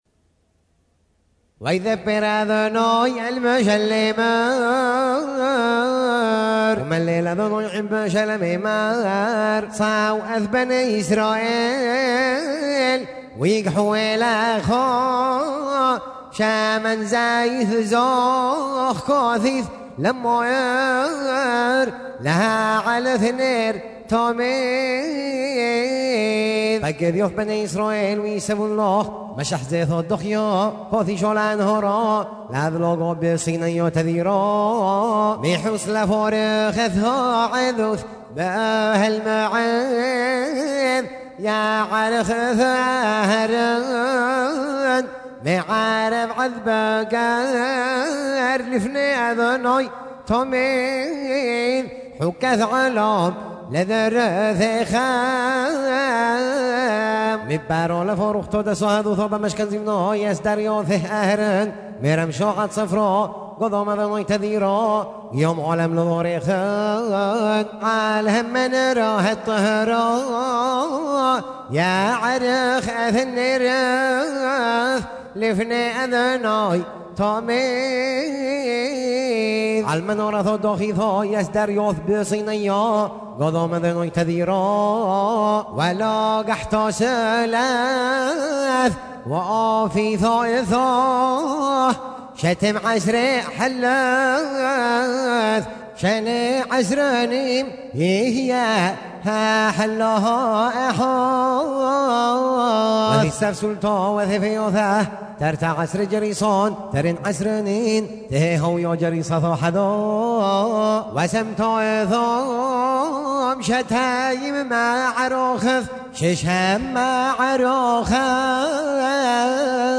ז'אנר: Blues.